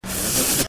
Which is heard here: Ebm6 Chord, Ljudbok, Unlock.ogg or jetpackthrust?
jetpackthrust